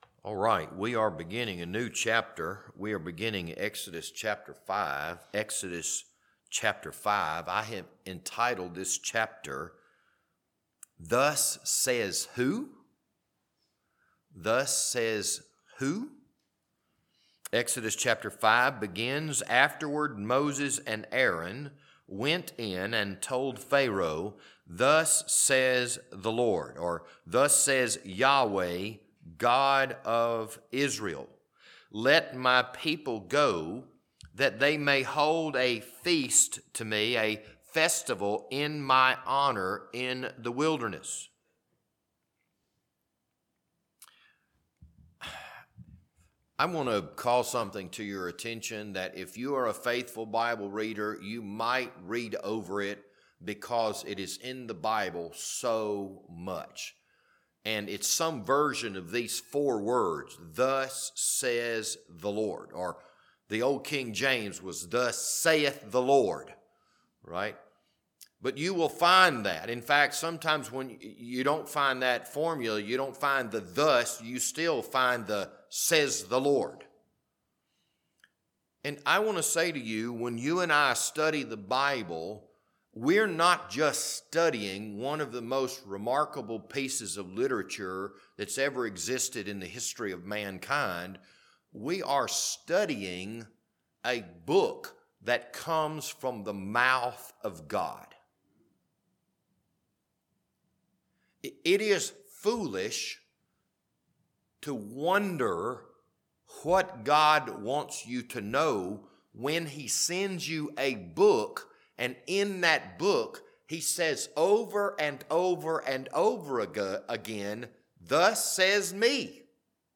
This Wednesday evening Bible study was recorded on February 26th, 2025.